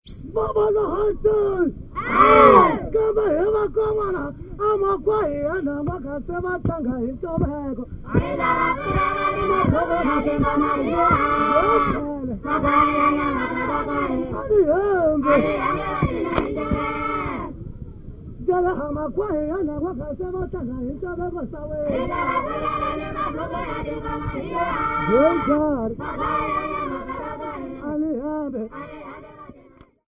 HTFT370-J21-H1R6.mp3 of Kwaya